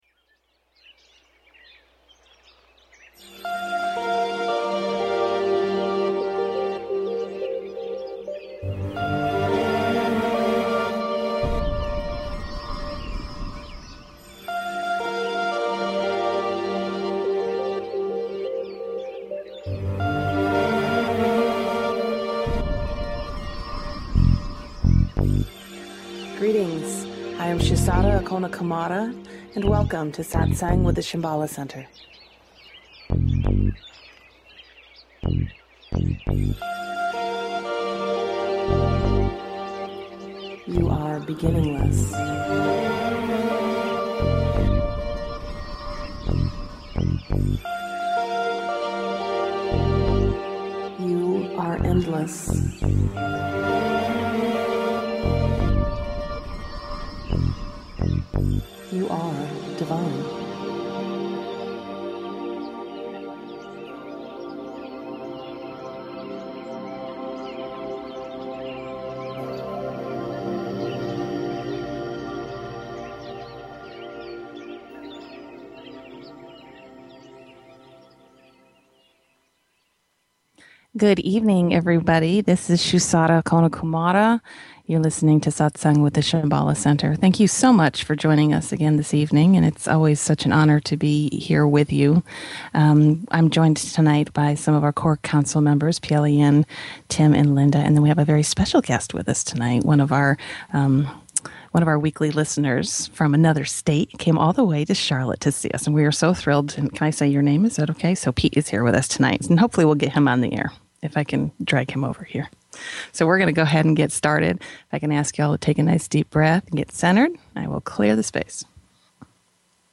Talk Show Episode
The guide facilitates the session by holding the energy for the group, providing teachings, answering questions and facilitating meditations.